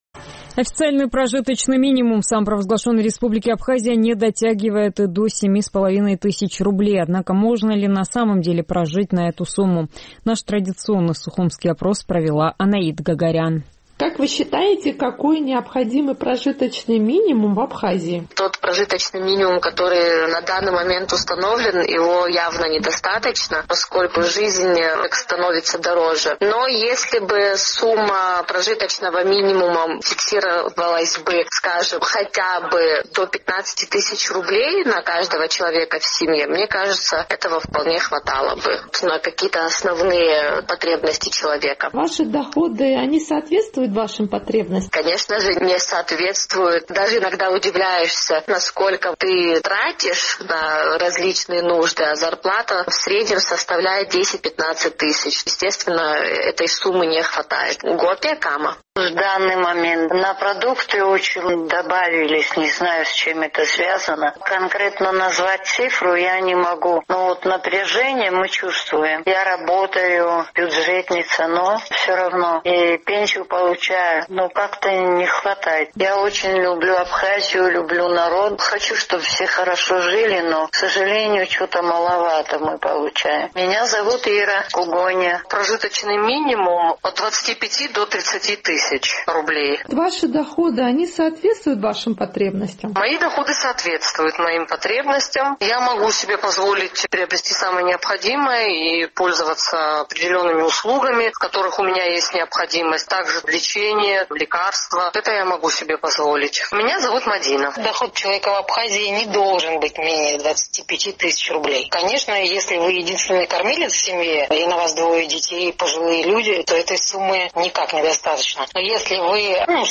Официальный прожиточный минимум в Абхазии не дотягивает и до семи с половиной тысяч рублей, однако можно ли на самом деле прожить на эту сумму? Наш традиционный сухумский опрос.